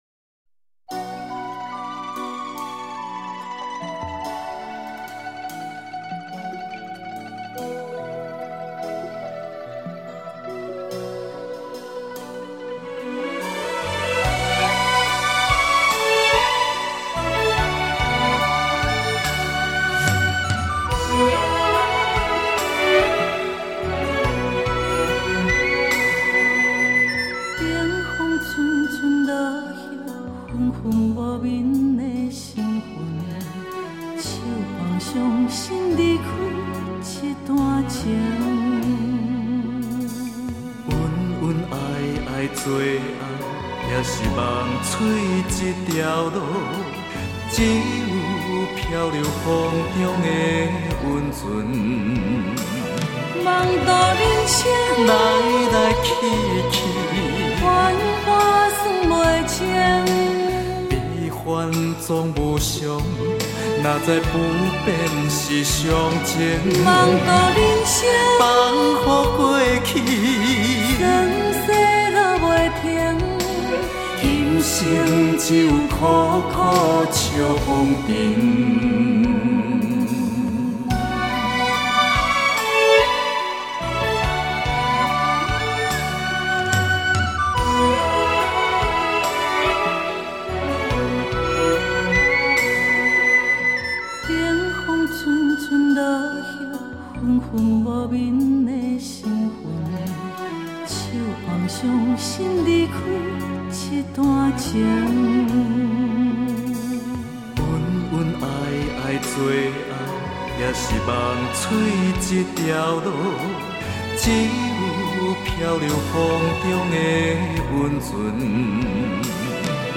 流 派: 流行